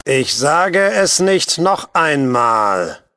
Kategorie:Fallout: Audiodialoge Du kannst diese Datei nicht überschreiben.